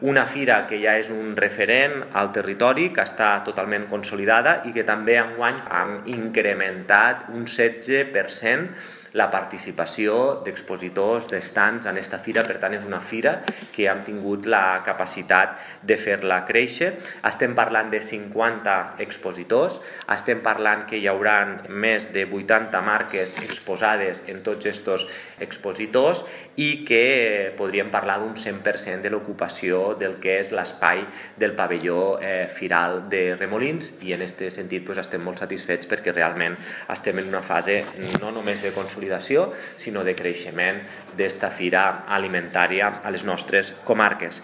L’alcalde de Tortosa, Jordi Jordan ha destacat que el certamen està en fase de creixement…